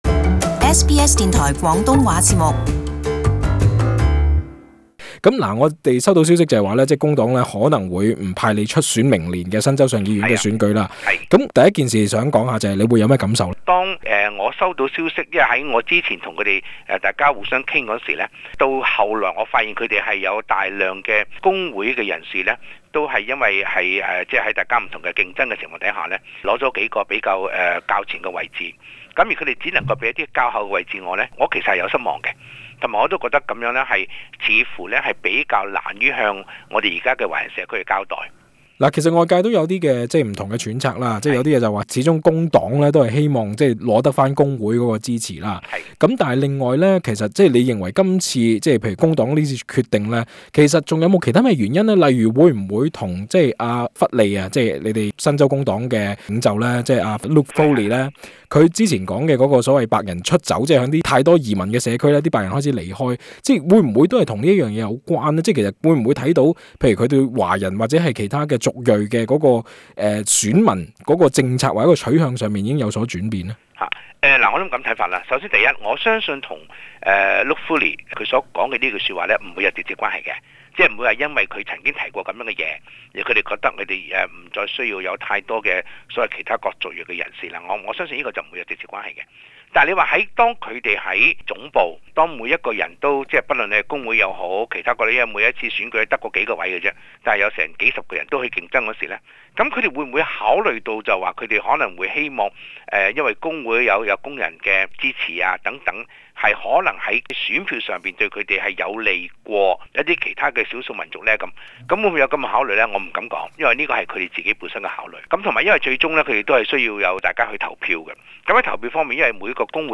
【時事專訪】王國忠親自證實明年州選未獲工黨支持